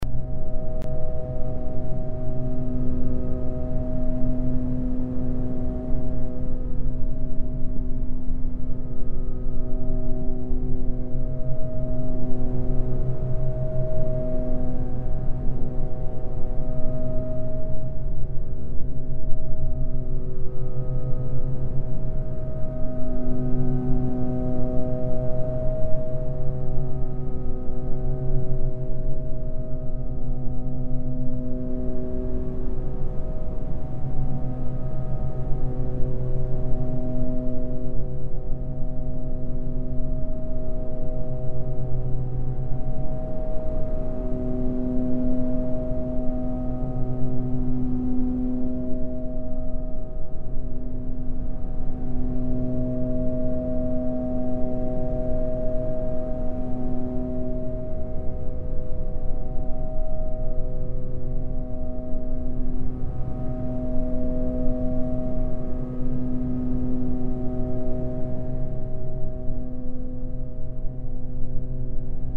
File:Hotel Ambience 1.mp3